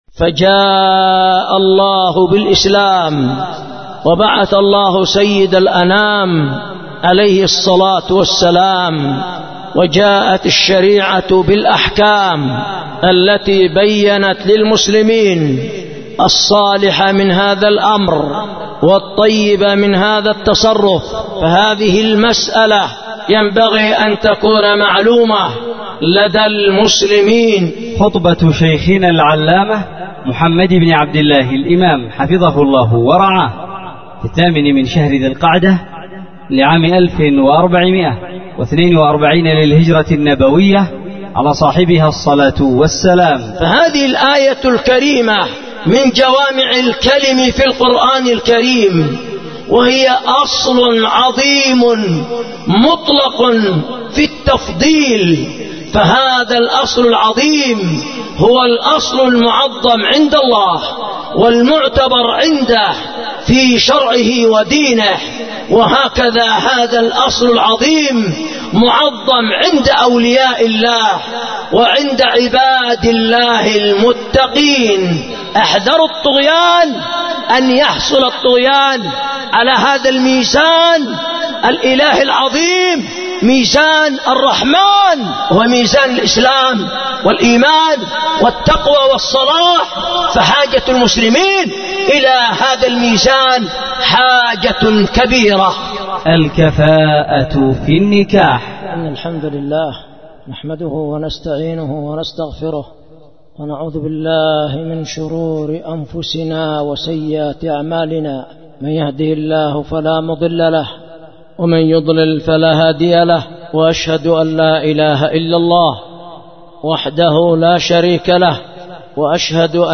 الكفاءة في النكاح الكفاءة في النكاح خُطبة لفضيلة الشيخ العلامة
ألقيت بـ دار الحديث بمعبر حرسها الله ذمار_اليمن